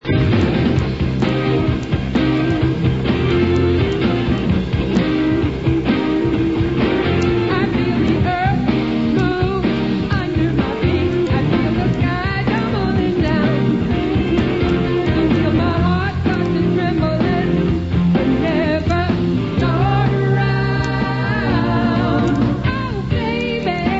sur scène à New York en 1995